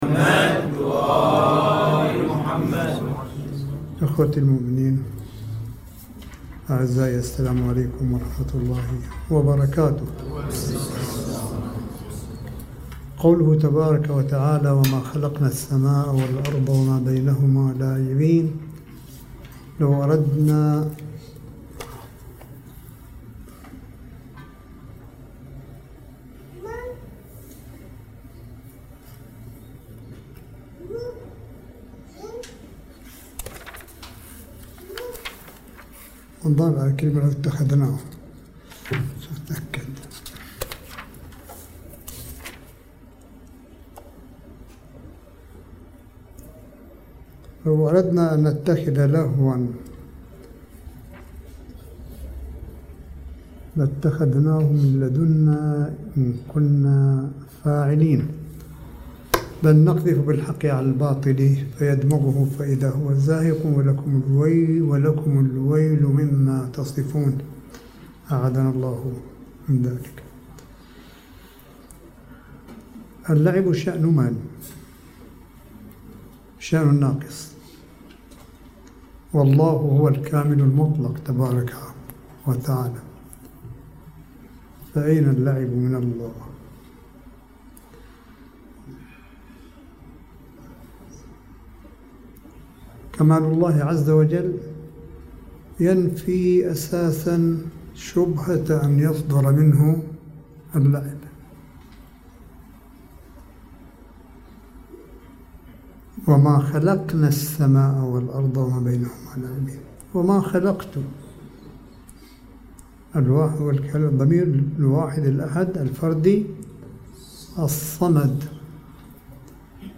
ملف صوتي لكلمة سماحة آية الله الشيخ عيسى أحمد قاسم بمناسبة ذكرى انطلاق ثورة ١٤ فبراير البحرانية سنة ٢٠١١ – مدرسة الامام الخميني (قده) في مدينة قم المقدسة ١٣ فبراير ٢٠٢٠م